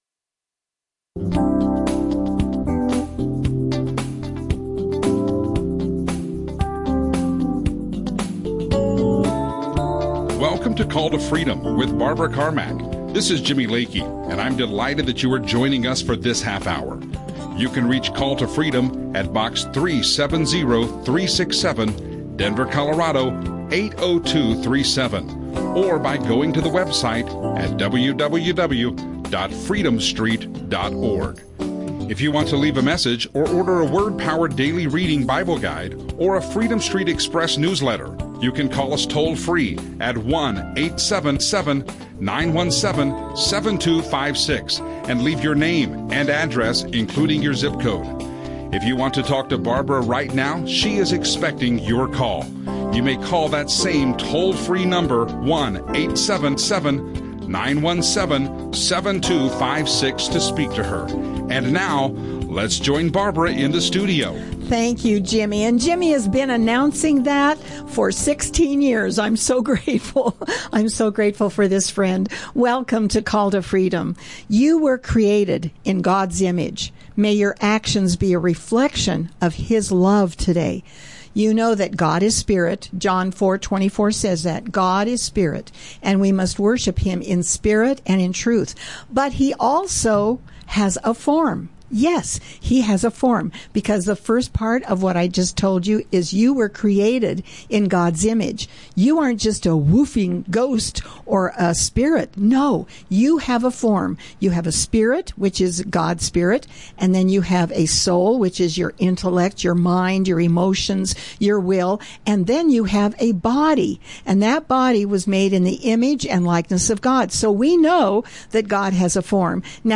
Christian radio show Christian talk